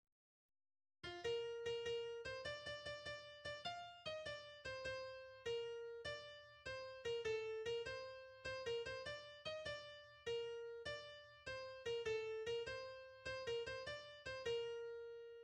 danse : ronde
Genre laisse